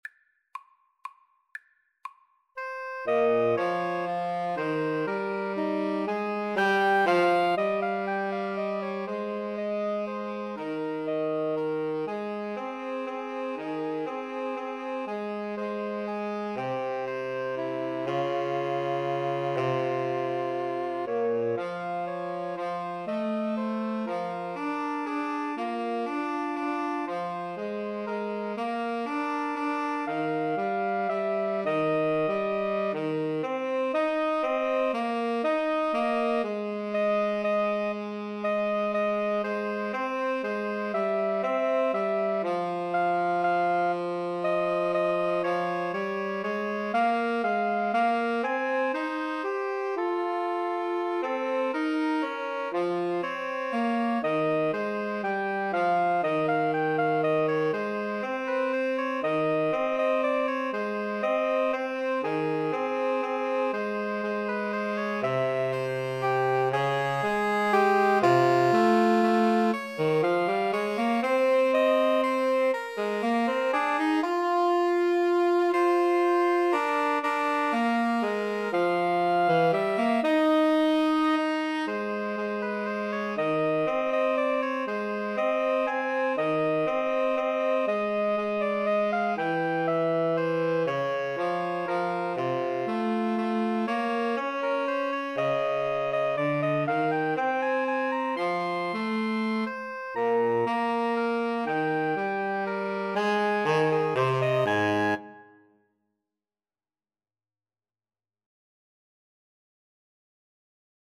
= 120 Tempo di Valse = c. 120